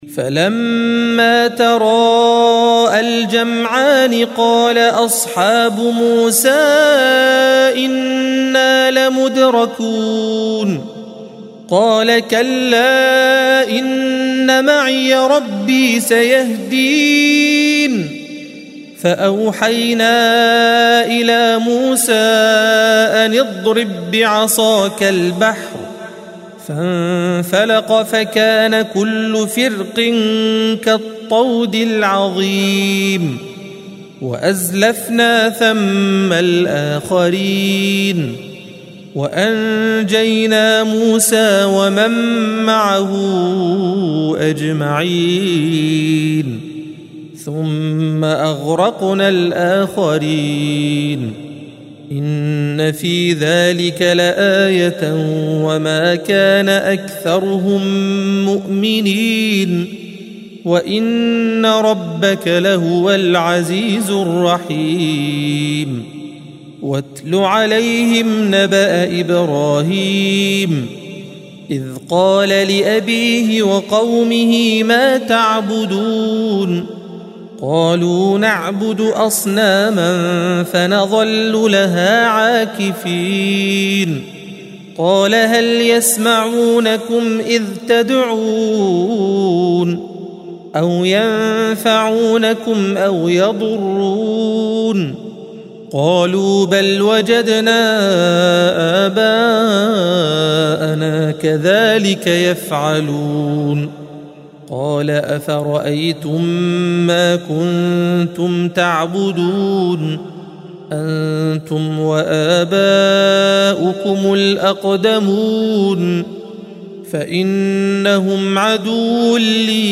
الصفحة 370 - القارئ